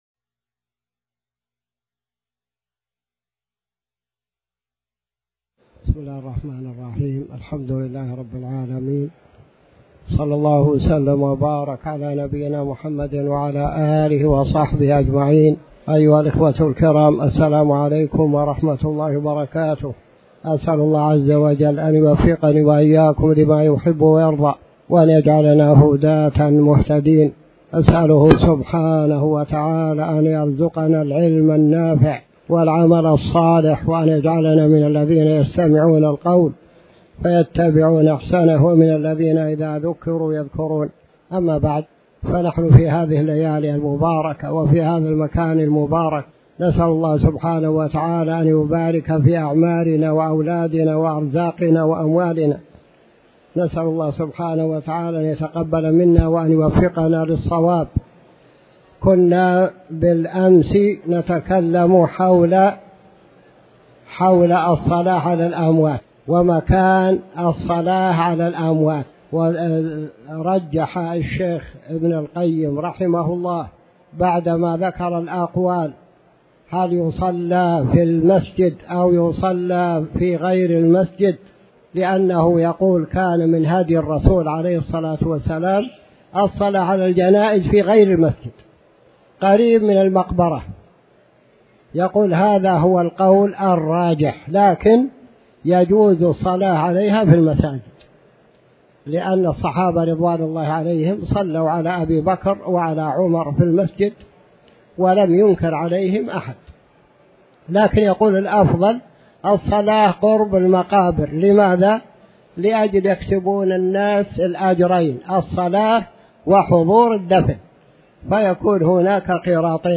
تاريخ النشر ٧ محرم ١٤٤٠ هـ المكان: المسجد الحرام الشيخ